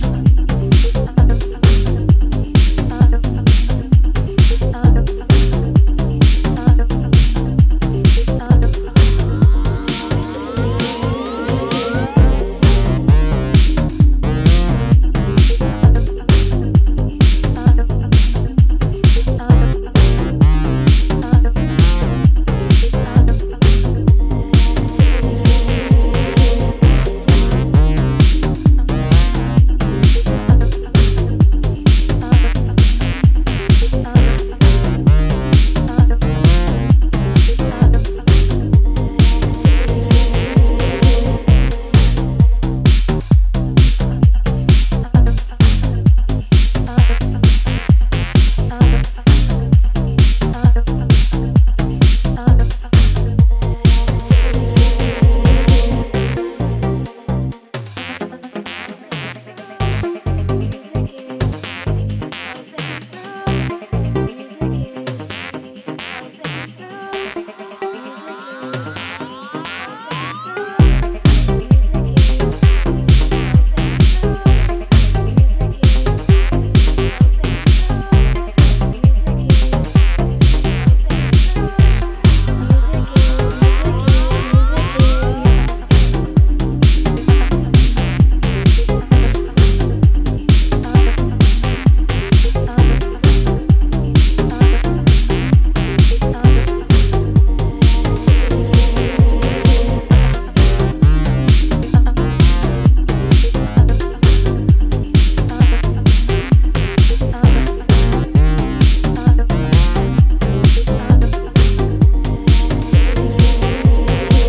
dreamy and bouncy